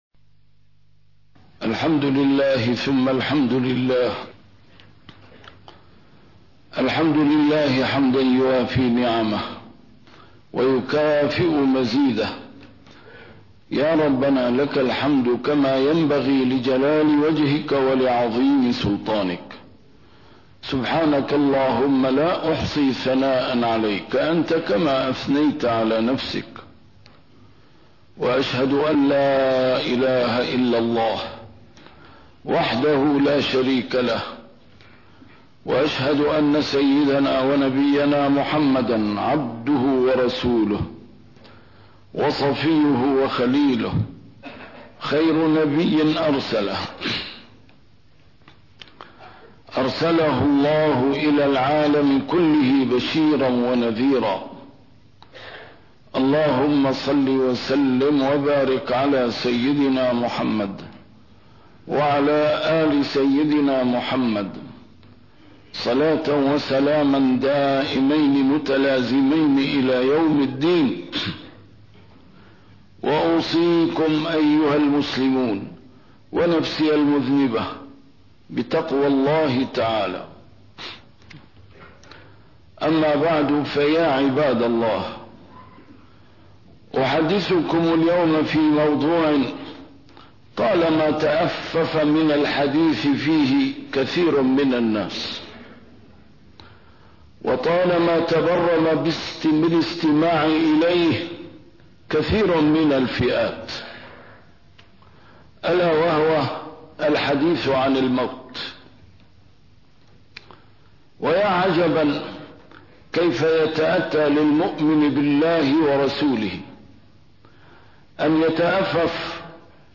A MARTYR SCHOLAR: IMAM MUHAMMAD SAEED RAMADAN AL-BOUTI - الخطب - المــوت... الحقيقة المنسية